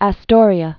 (ă-stôrē-ə)